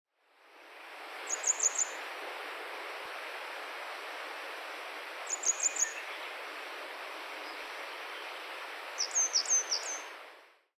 今回は、最初、長い尾羽を振りながら歩いていたが、ぴょんと高い所にとまって、見事なさえずりを聞かせてくれた。
【録音③】　キセキレイ（さえずり）　2024年5月
（鳴き声の特徴）最初に「チチチチチ」と鳴き、もう一度「チチチチチ」、間を置いてから「ツリツリツリ」と繰り返す。